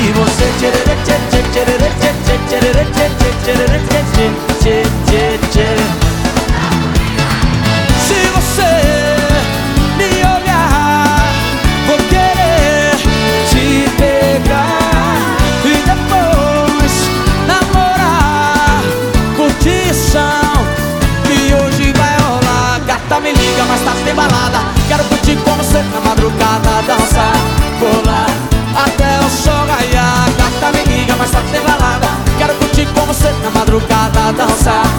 # Sertanejo